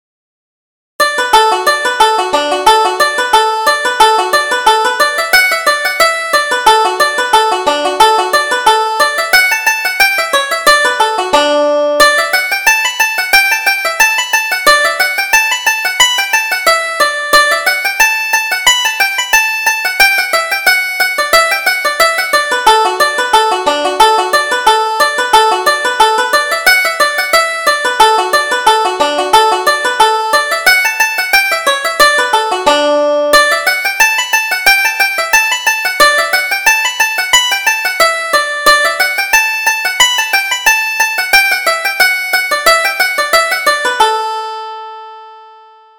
Reel: Over the Bridge to Peggy